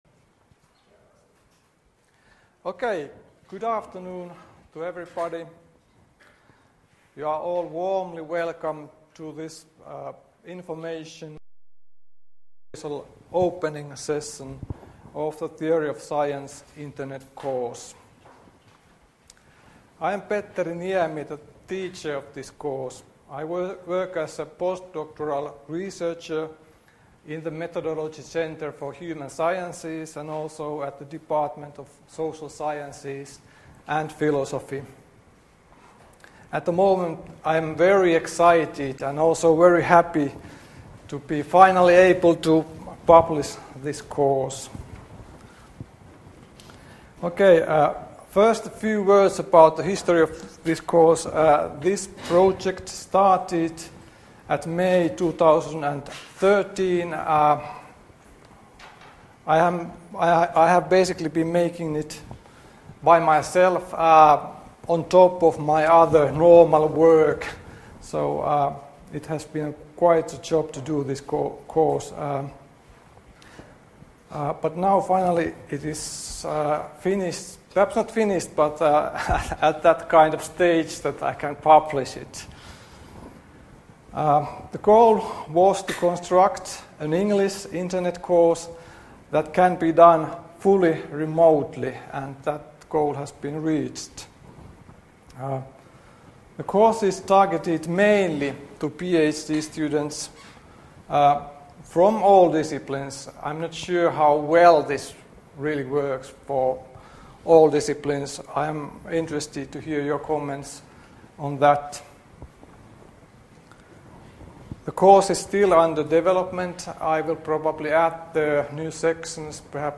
Information session 12.1.2015